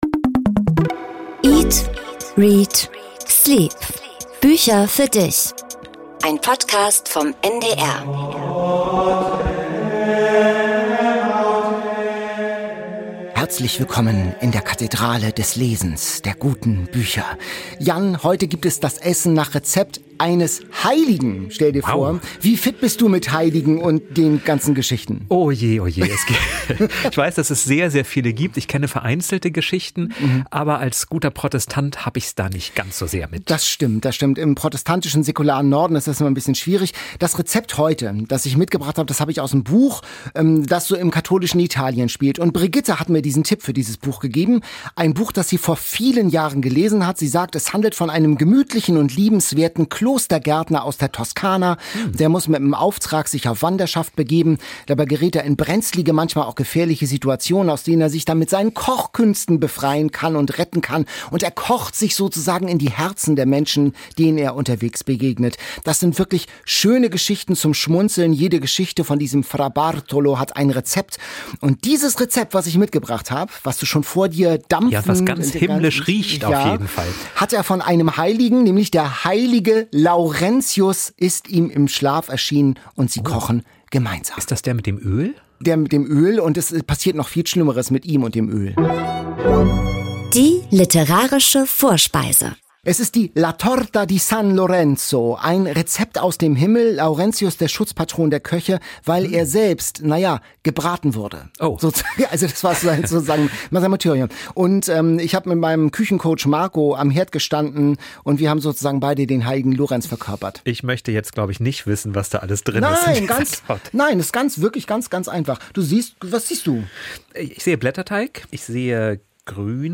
00:25:50 Interview mit Olga Grjasnowa